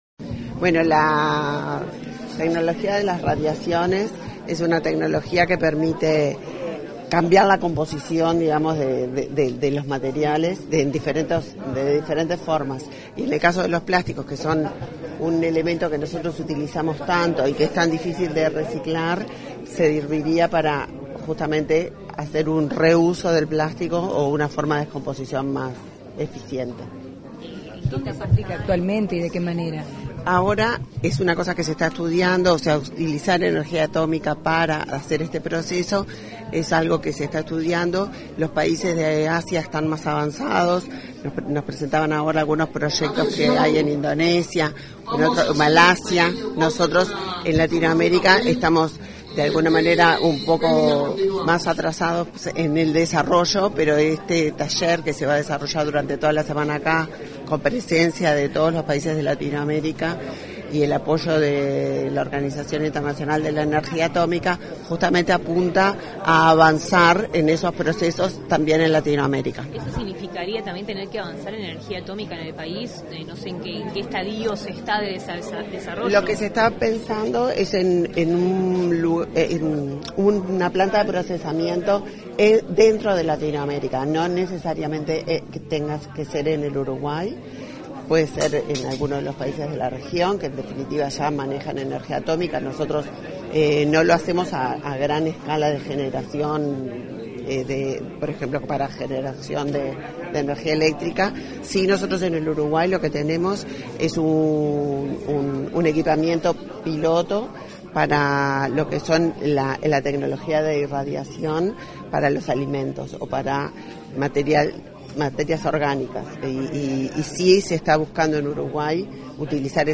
Declaraciones de la ministra de Industria, Energía y Minería, Elisa Facio
Tras el Taller Regional sobre el Diseño de Instalaciones de Irradiación para el Reciclado de Plástico y su Viabilidad Económica, este 9 de diciembre,
Declaraciones de la ministra de Industria, Energía y Minería, Elisa Facio 09/12/2024 Compartir Facebook X Copiar enlace WhatsApp LinkedIn Tras el Taller Regional sobre el Diseño de Instalaciones de Irradiación para el Reciclado de Plástico y su Viabilidad Económica, este 9 de diciembre, la ministra de Industria, Energía y Minería, Elisa Facio, dialogó con los medios informativos presentes.